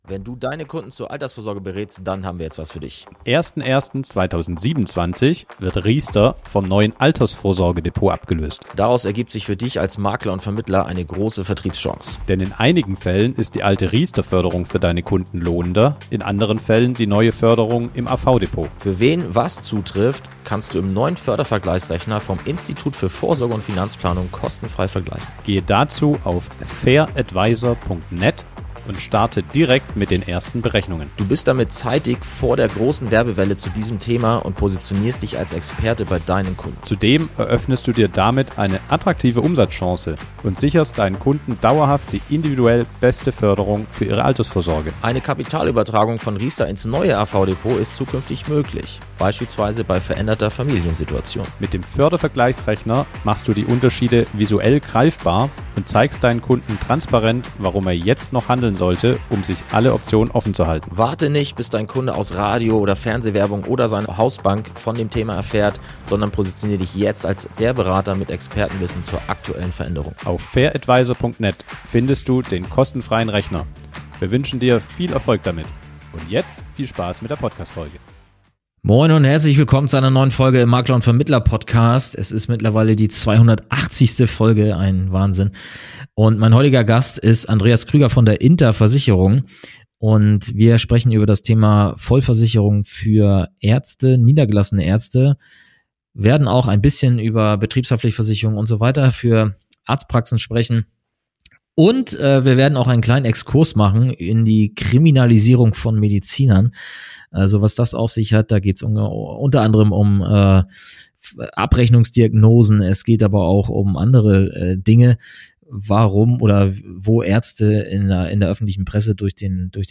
Im Gespräch erfährst du, wie Vermittler sich dieser besonderen Zielgruppe nähern, worauf Ärzte und Zahnärzte bei Berufshaftpflicht, Rechtsschutz und Krankenversicherung achten sollten und welche Lösungen die INTER speziell anbietet. Außerdem beleuchten die beiden aktuelle Themen wie die mediale „Kriminalisierung“ von Ärzten und Herausforderungen bei Abrechnungen.